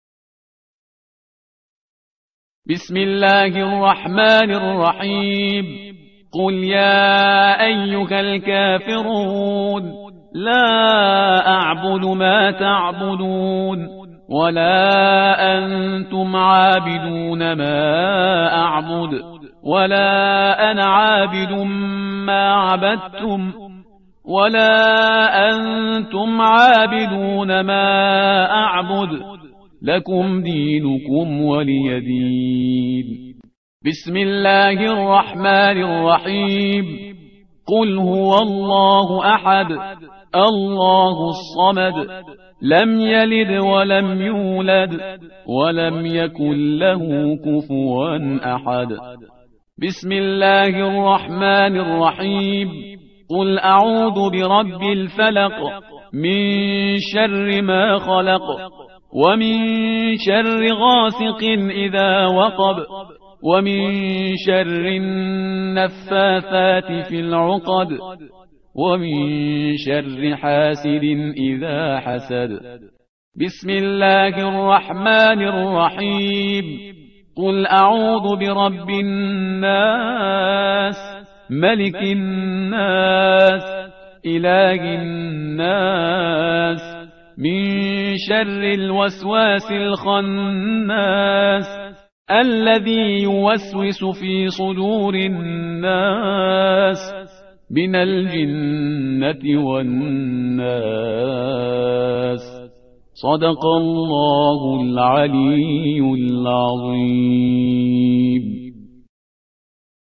با صدای: استاد شهریار پرهیزکار